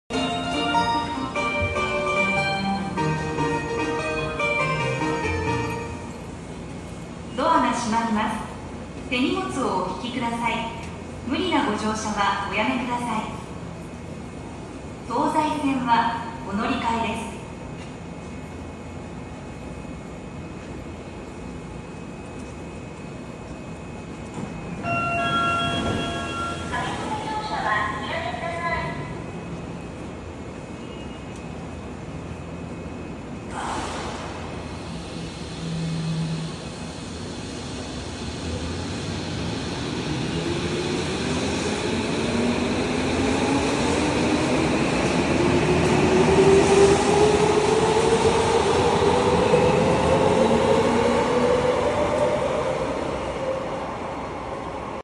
🇯🇵Departure Melody at Kayabacho Station